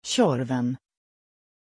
Aussprache von Tjorven
pronunciation-tjorven-sv.mp3